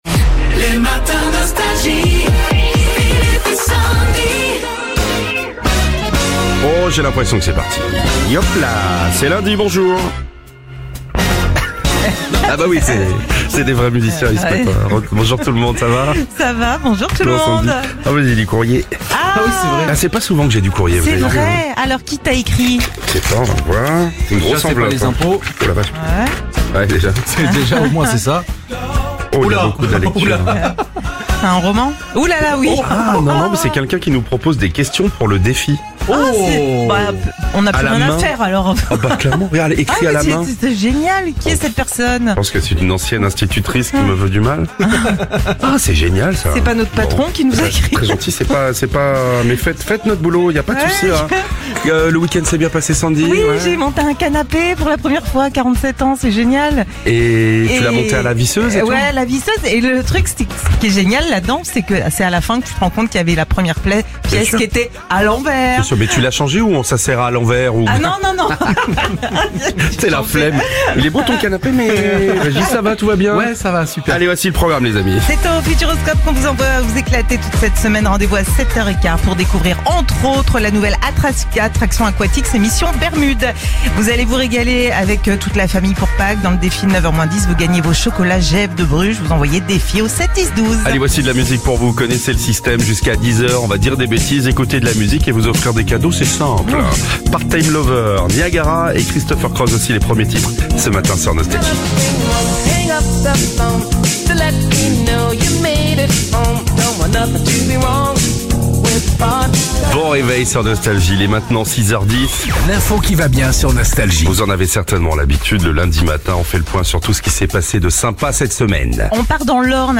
Au programme : Bonne humeur et tous les tubes 80 !